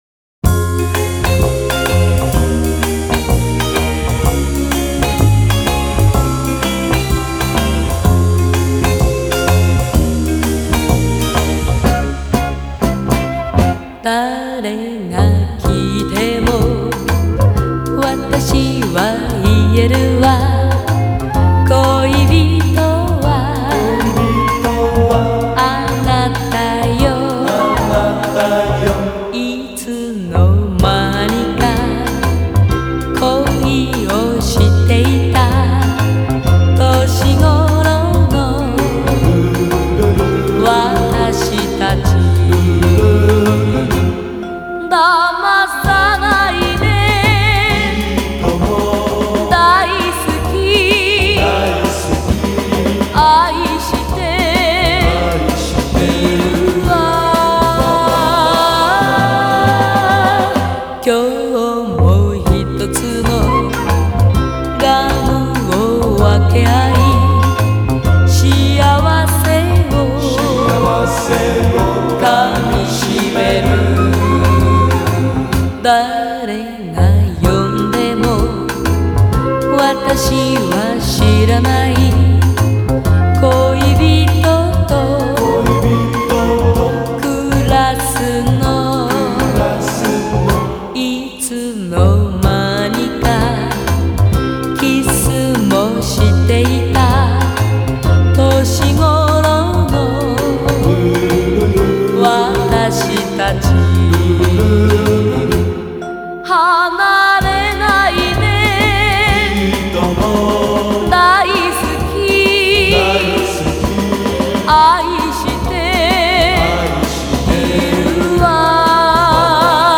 Стиль музыки: j-pop / retro